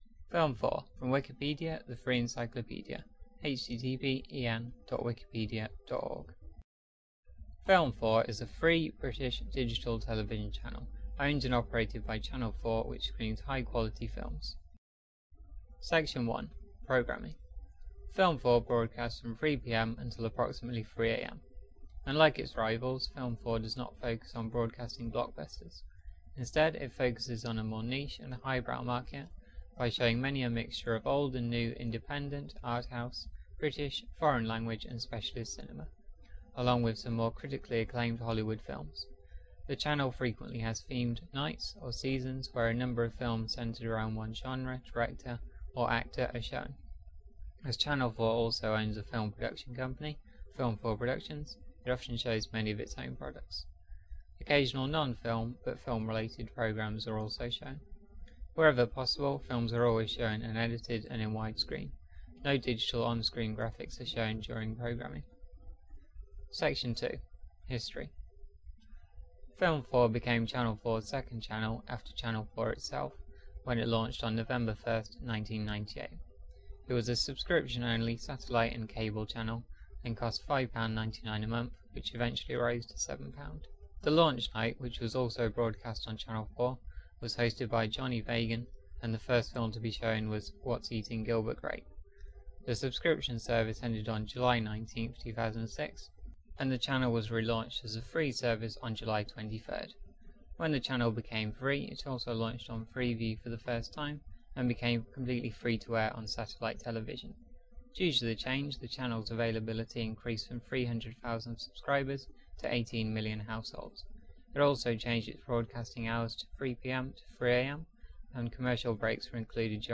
This is a spoken word version of the Wikipedia article: Film4
English w:en:Received Pronunciation
• Own recording by the speaker